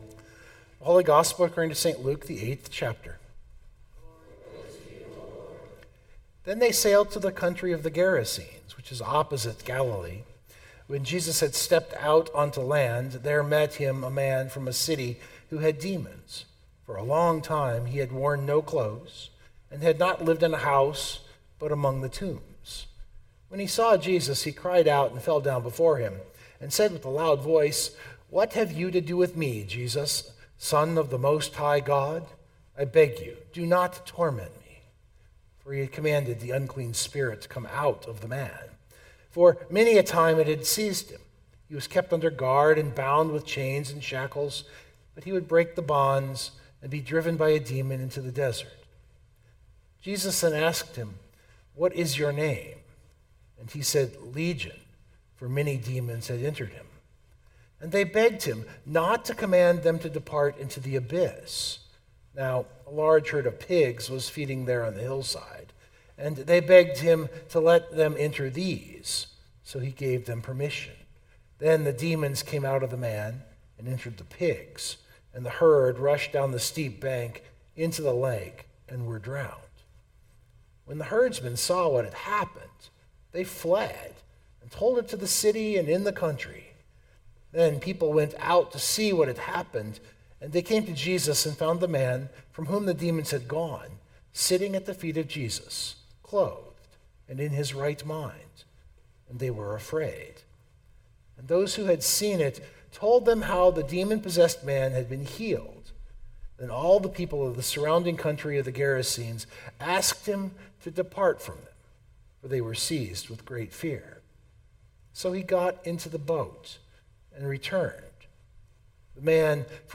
062225 Sermon Download Biblical Text: Luke 8:26-39 In the church year it is the start of the long green season.